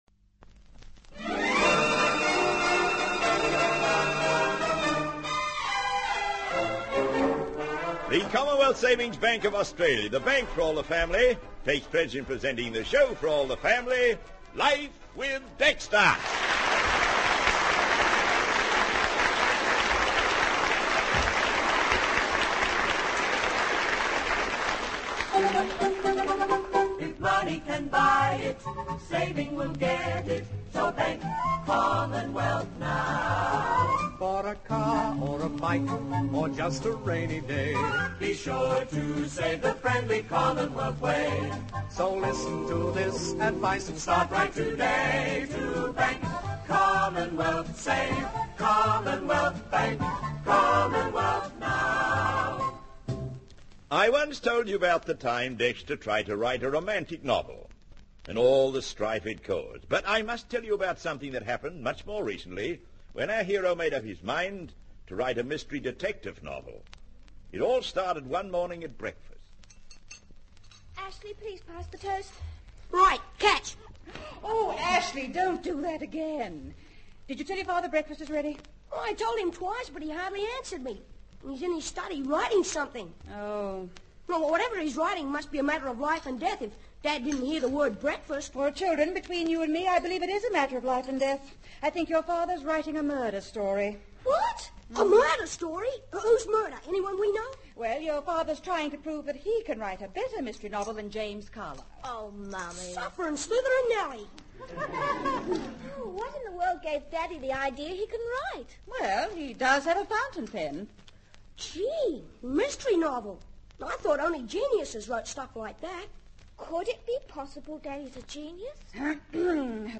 "Life with Dexter" was a popular Australian radio comedy program that aired from the 1950s through to the mid-1960s.